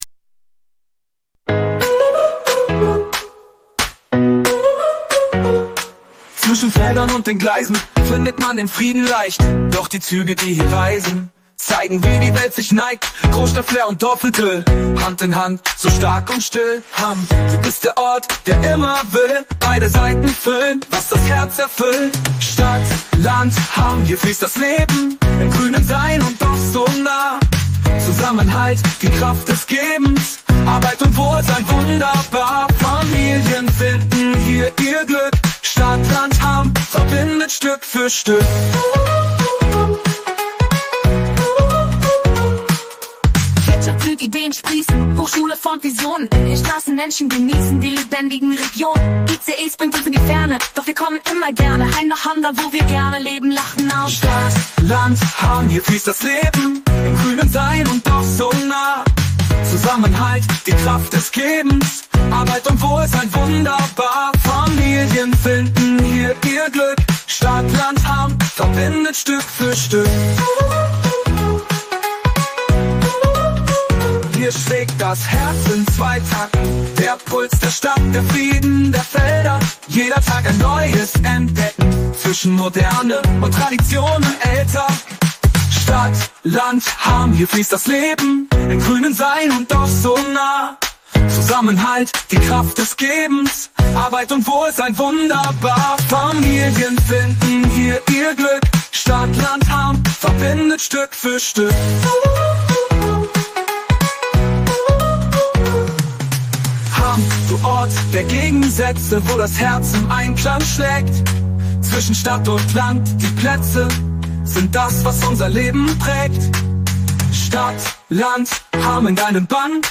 Stadt Land Hamm – Musik Datei Mitschnitt MP3 zum Anhören (Wenn man es mag.